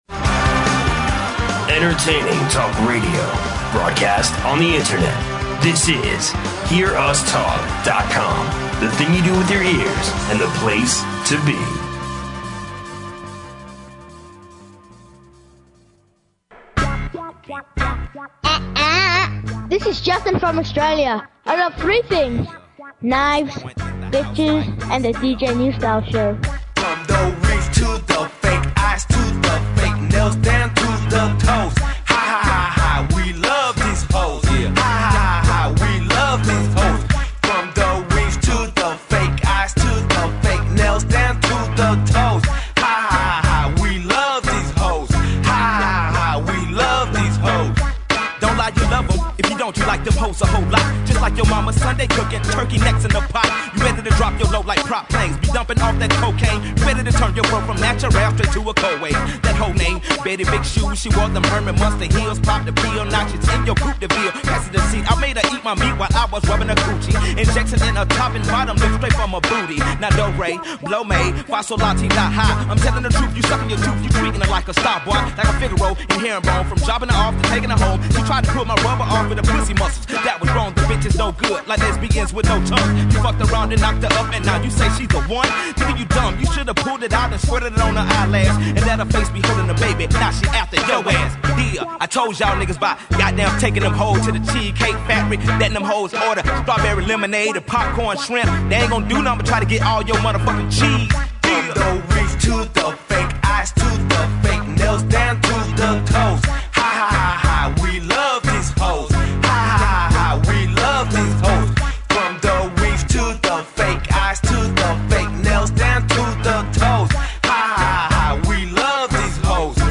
A barrage of awful calls.